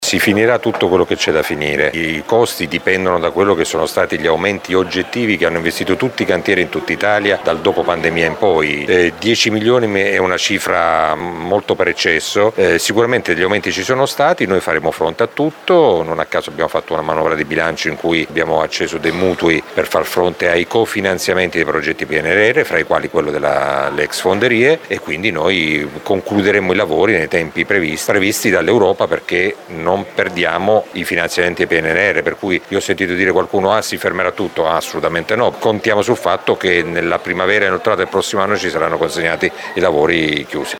Il sindaco Mezzetti ribadisce: i tempi del cantiere saranno rispettati: